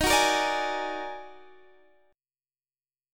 Listen to D#mM11 strummed